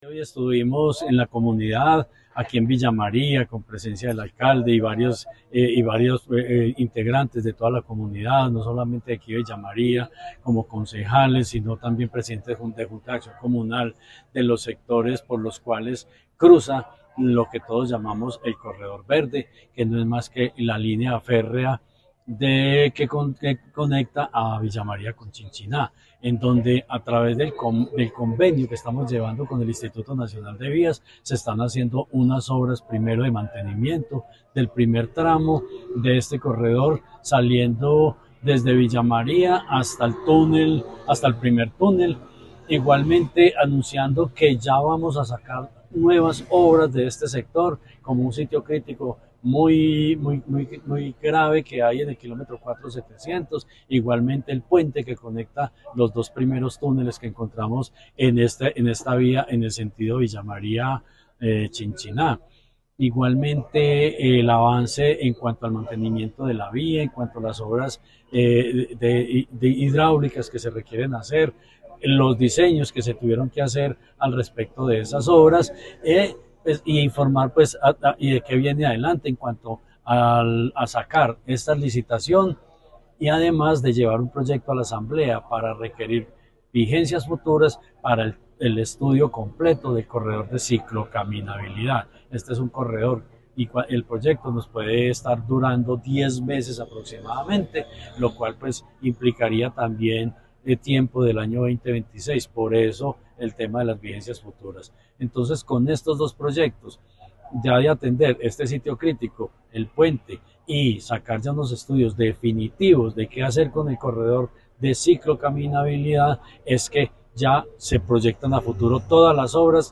Secretario de Infraestructura de Caldas, Jorge Ricardo Gutiérrez Cardona.